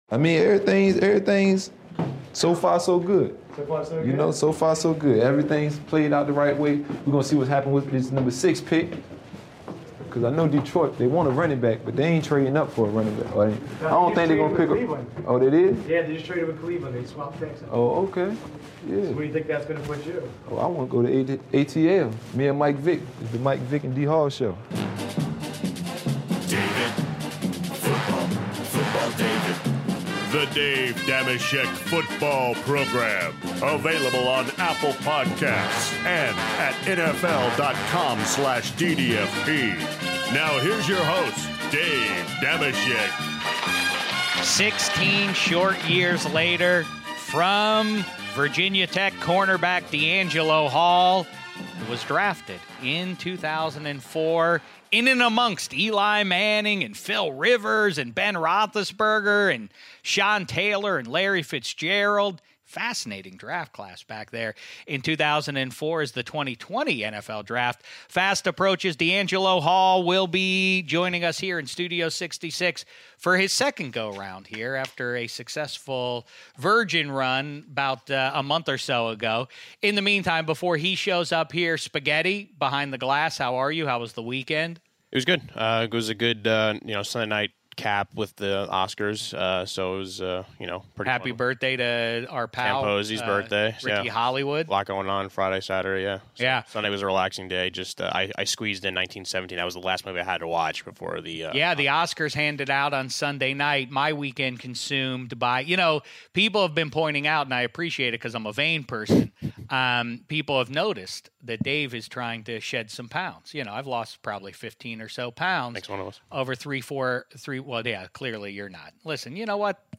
Dave Dameshek starts the show solo in Studio 66 but eventually is joined by our old pal DeAngelo Hall!